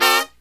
FALL HIT05-R.wav